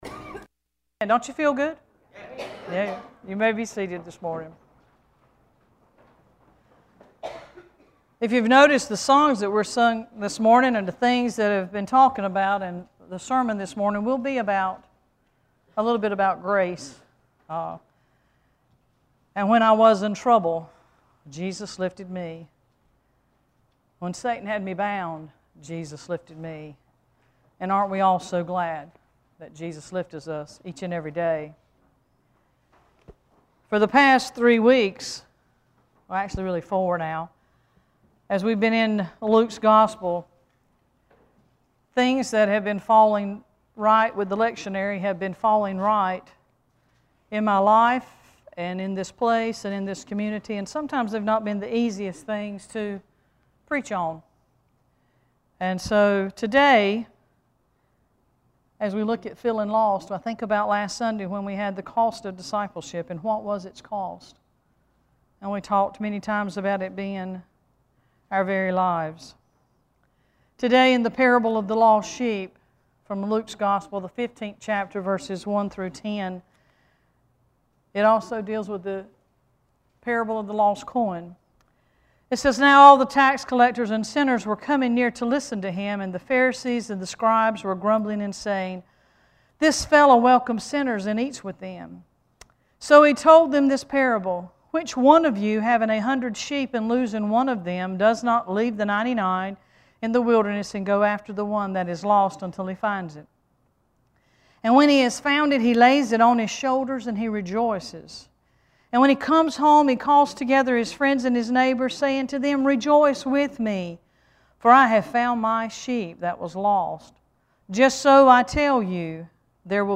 Worship Service 9-15-13: Feeling Lost
scripture-9-15-13.mp3